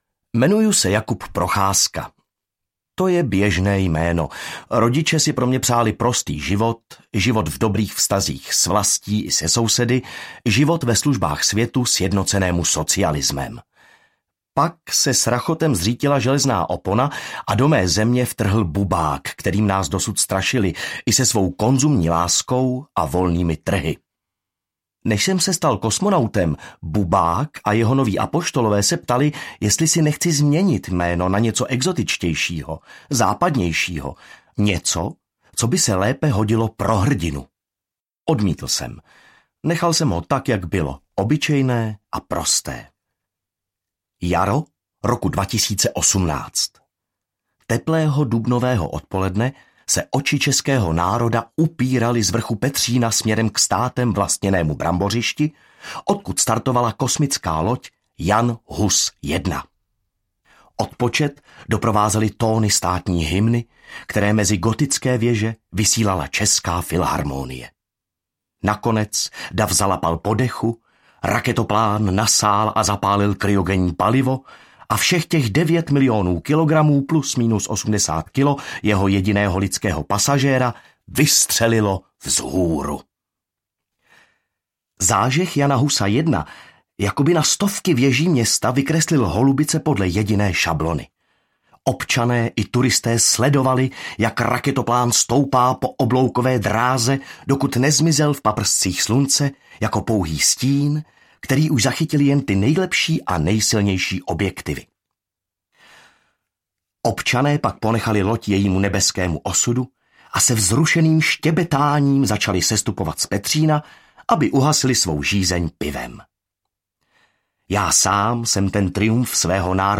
Kosmonaut z Čech audiokniha
Ukázka z knihy